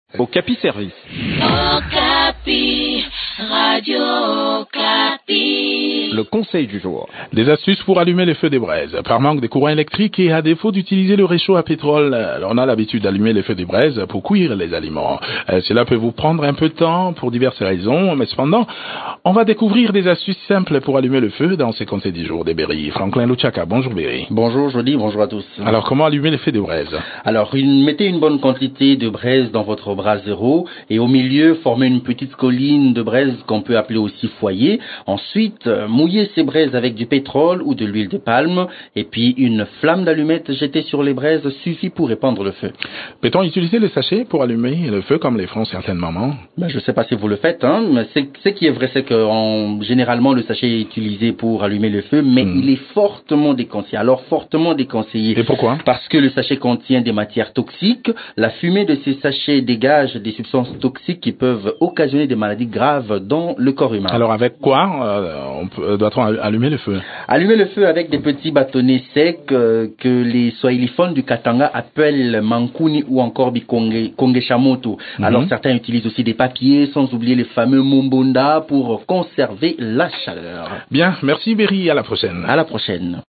Découvrez quelques astuces qui peuvent vous aider à allumer facilement la braise dans cette chronique